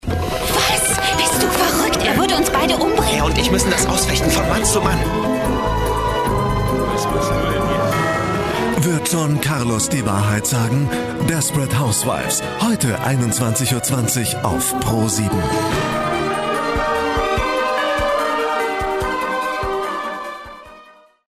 deutscher Sprecher, Moderator und Schauspieler mit bekannter und markanter TV-Stimme. Charakteristik: Tief und warm - jung und wandelbar
Sprechprobe: Industrie (Muttersprache):
german voice over talent, references: Milka, Allianz, Lufthansa, DB u.a.